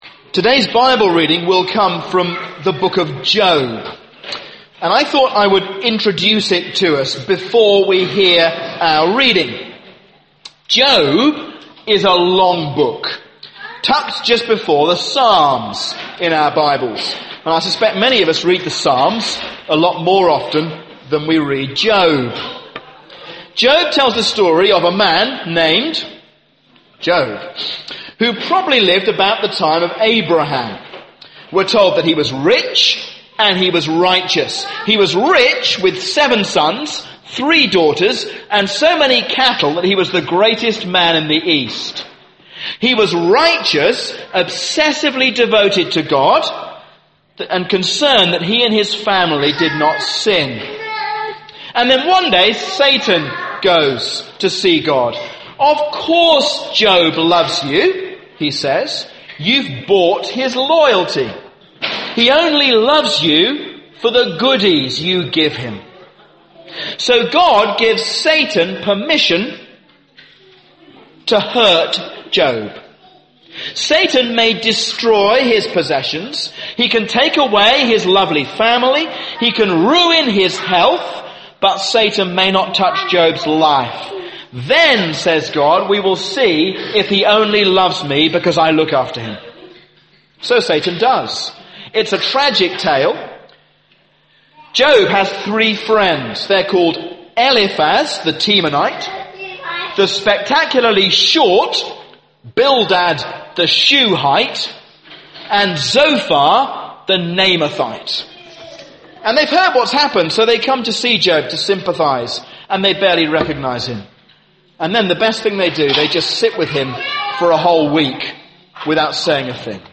A sermon on Job 9